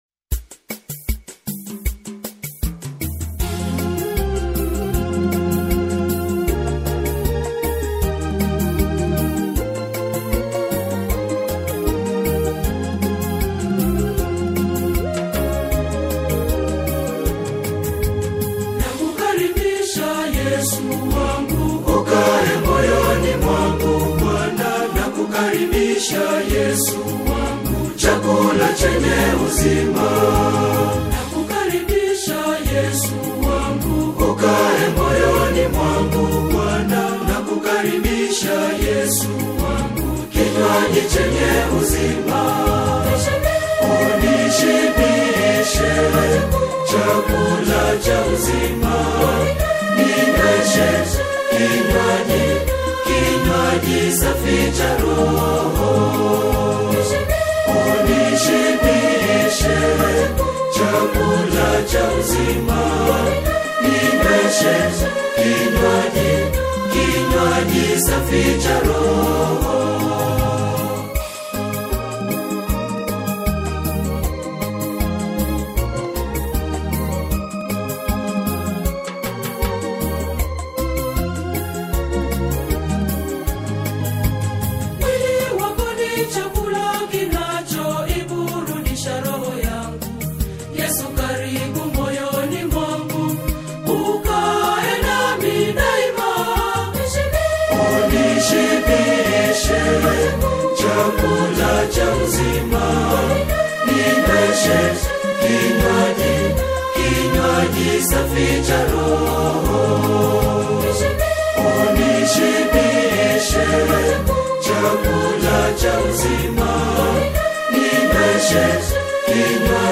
gospel tune